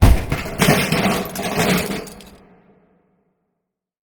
projectorFallReverb.ogg